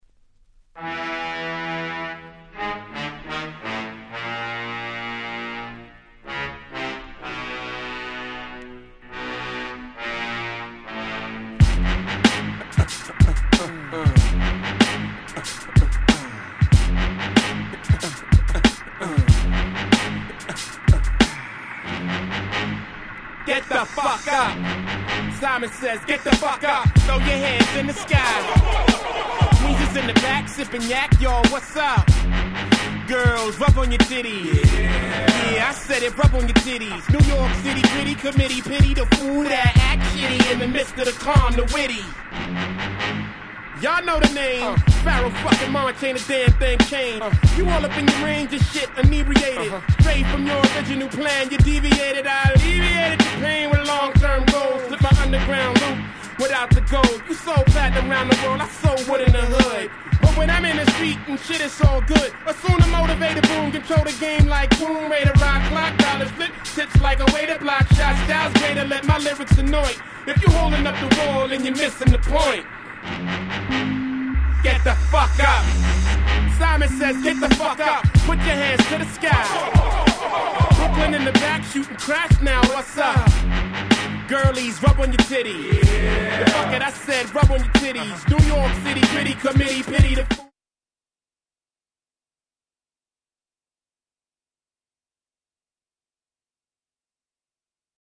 ※一部試聴ファイルを別の盤から録音しております。
99' Super Hit Hip Hop !!